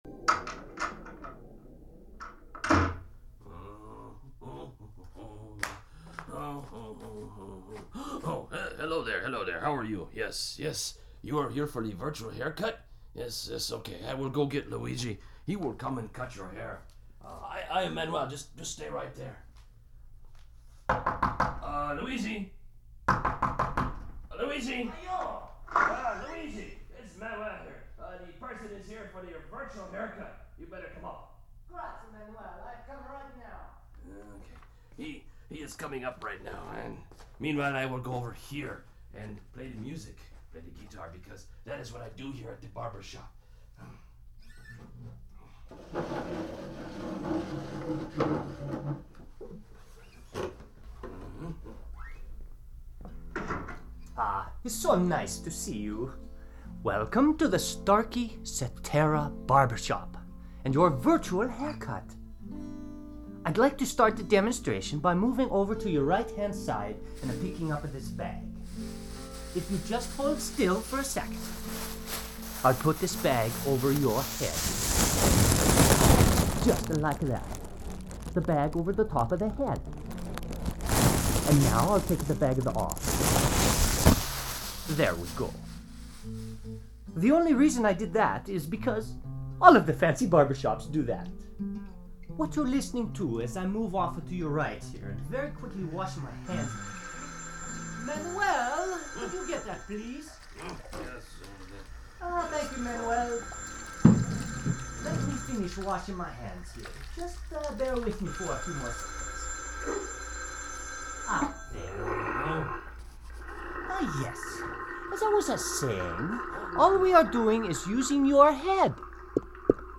Binaural recordings record sounds by placing a dummy head - a simulation of a real human head, with shaped ears that modulate sounds - with a right and left microphone inside it into a recording room. This is done with the intention of creating a "three-dimensional" sound, causing the listener to feel like they're in a room with people and objects moving around them - and it's extraordinarily effective.
This video demonstrates the illusion of 3D sound (note: you will need headphones for it to work!!).
And if you're listening to this late at night, don't be freaked out by that opening door like I was, haha.
virtual-barbershop.mp3